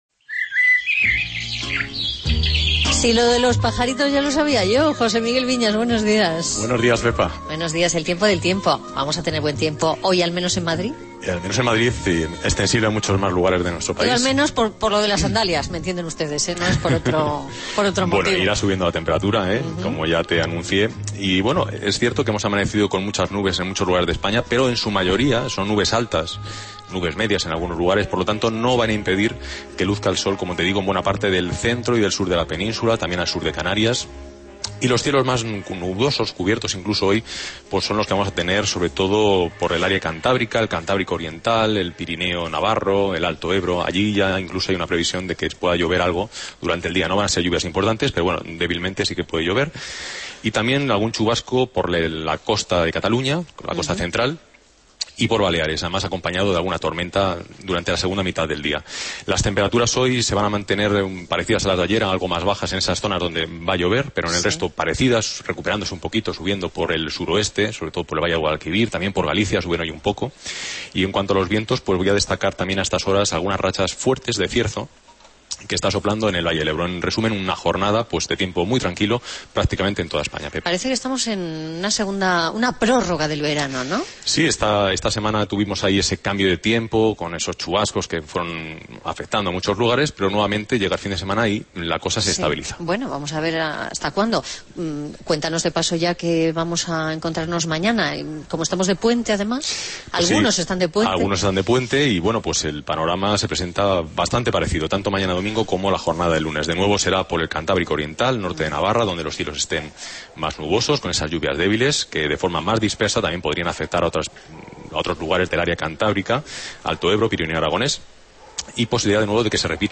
Esta mañana dediqué mi sección radiofónica "El tiempo del tiempo" al asunto éste de los túneles.